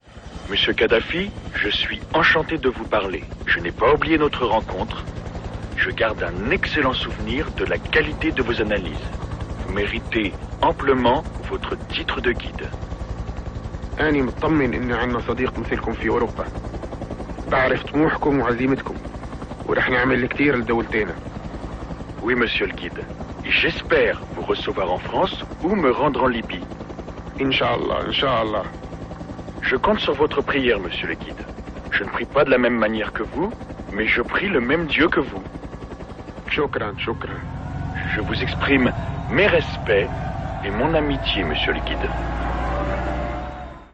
Je suis imitateur de politiques, chanteurs, sportifs, présentateurs…
Imitation Nicolas Sarkozy (fidèle et naturelle)